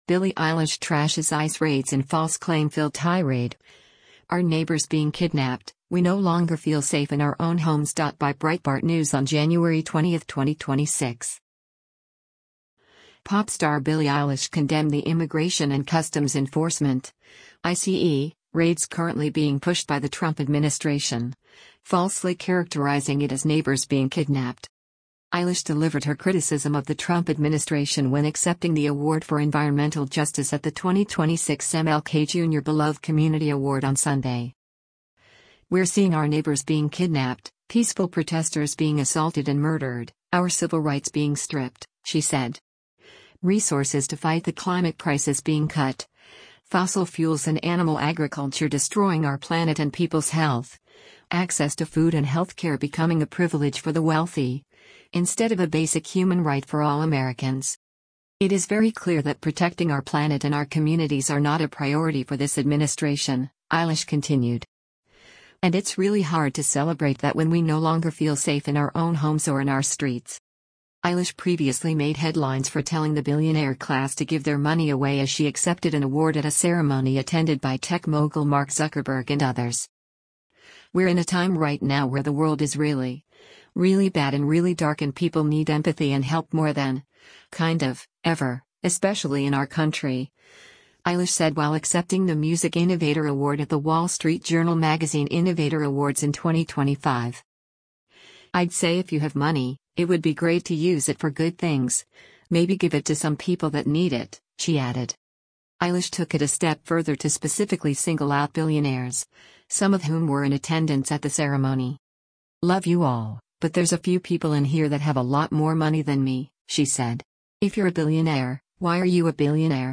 Eilish delivered her criticism of the Trump administration when accepting the award for Environmental Justice at the 2026 MLK Jr. Beloved Community Award on Sunday.